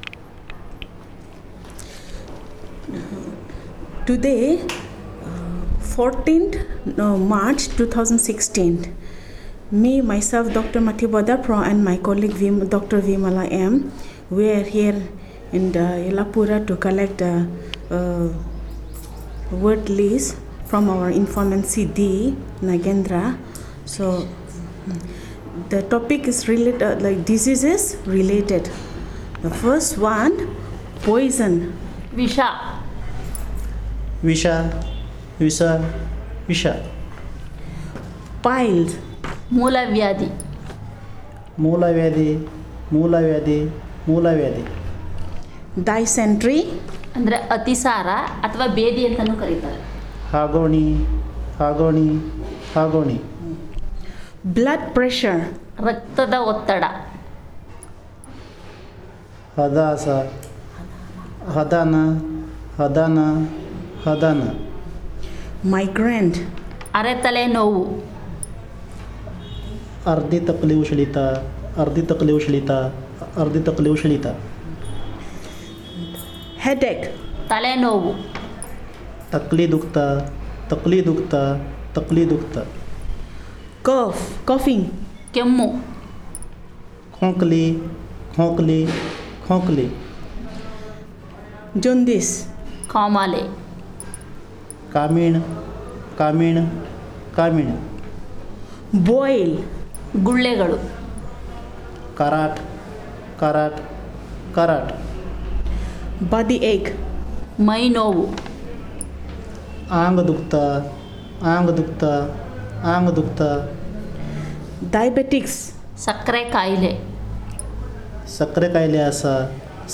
Elicitation of words about health ailments and remedies